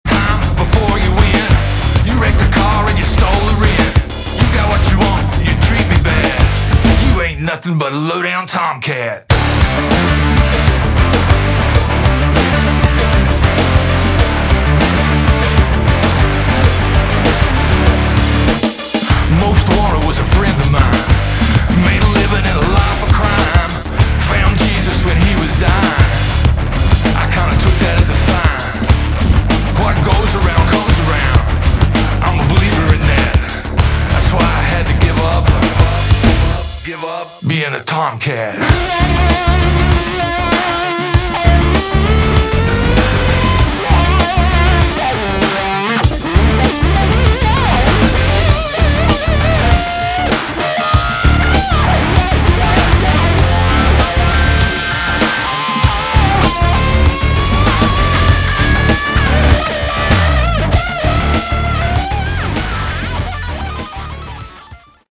Category: Hard Rock / Boogie Rock
lead vocals, guitar
lead guitars